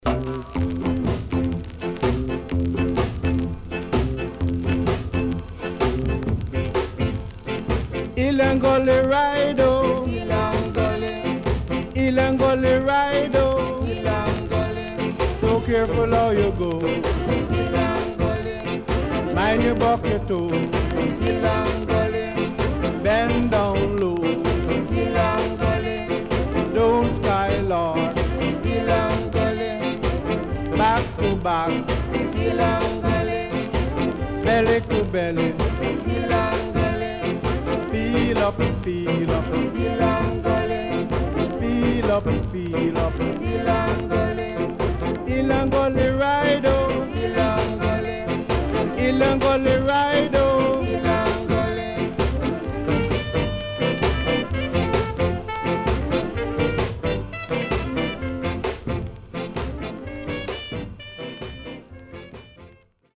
HOME > SKA / ROCKSTEADY  >  SKA  >  定番60’s
SIDE A:所々チリノイズがあり、少しプチノイズ入ります。